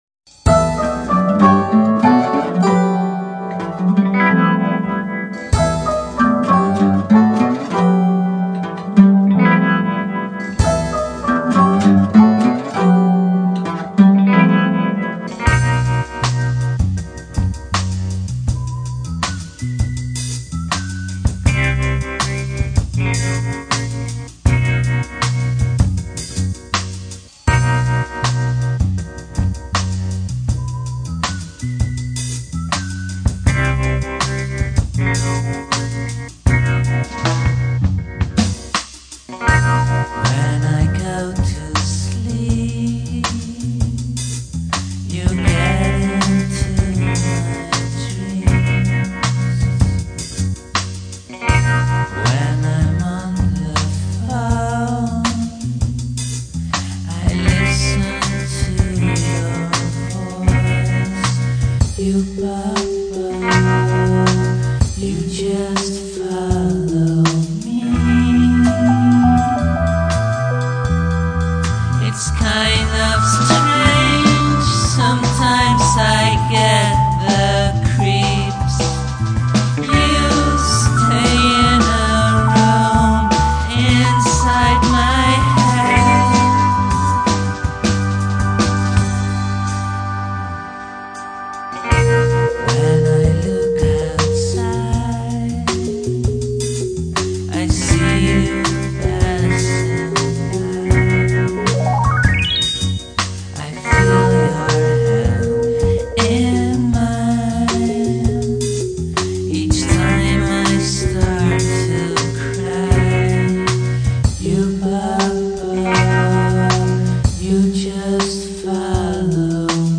where: Studio Aluna , Amsterdam